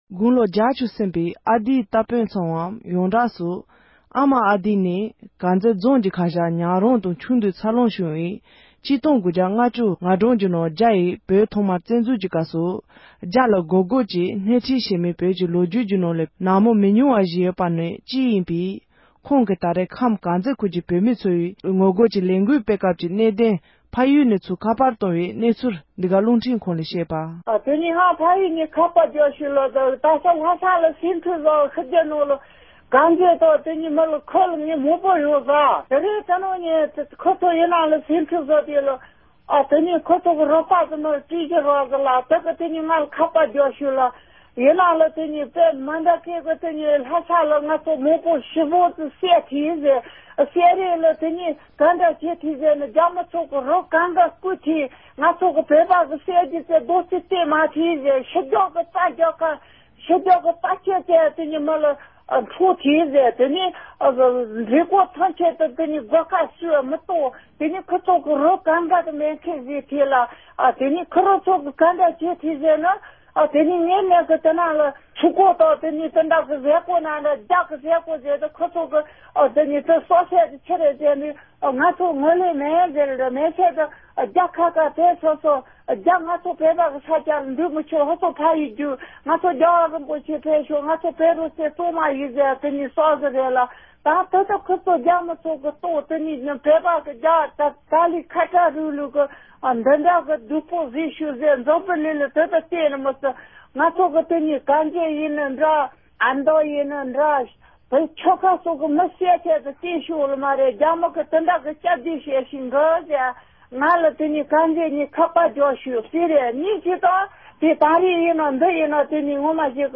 ༄༅༎ཐེངས་འདིའི་རང་དབང་གི་གླེང་མོལ་གྱི་ལེ་ཚན་ནང༌། དེ་སྔོན་རྒྱ་མིས་བོད་ས་བཙན་འཛུལ་སྐབས་ཁམས་དཀར་མཛེས་ཁུལ་གྱི་ངོ་རྒོལ་གྱི་ལས་འགུལ་སྣེ་ཁྲིད་མ་ཨ་མ་ཨ་རྡི་ལགས་ཀྱིས་ད་རེས་ངོ་རྒོལ་སྐོར་གནས་ཚུལ་བཤད་པ་གསན་རོགས་ཞུ༎